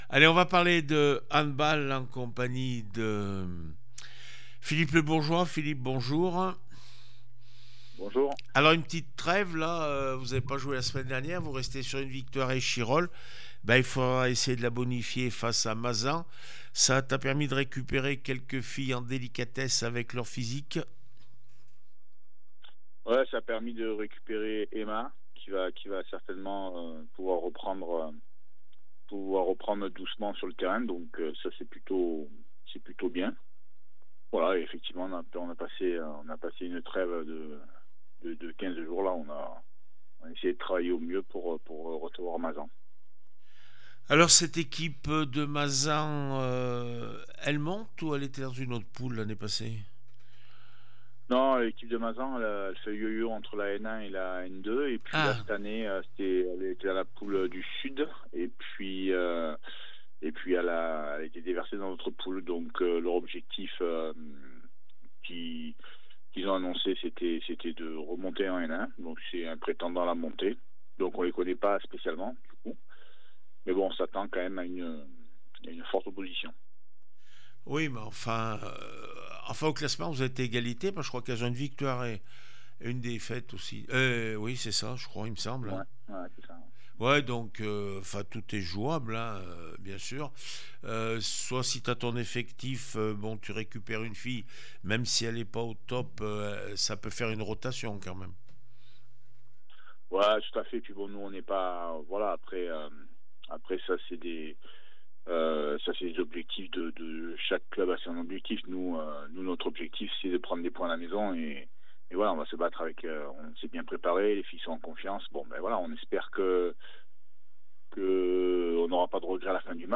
27 septembre 2025   1 - Sport, 1 - Vos interviews